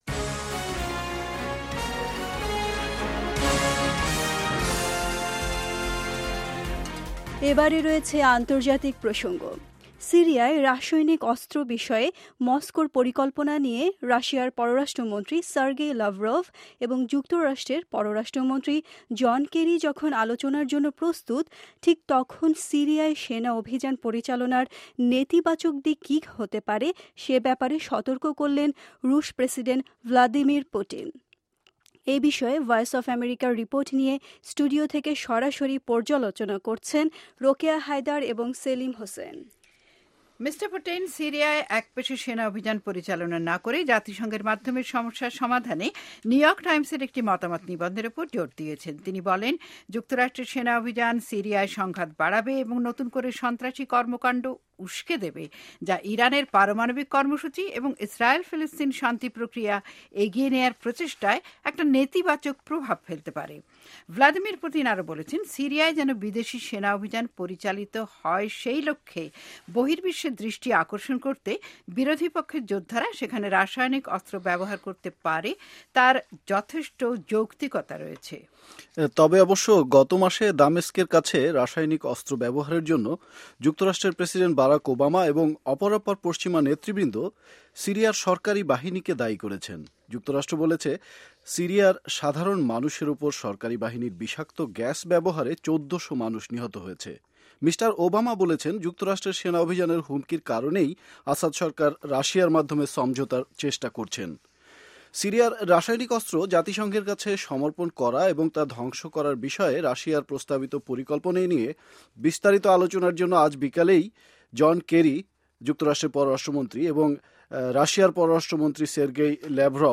studio round table on syria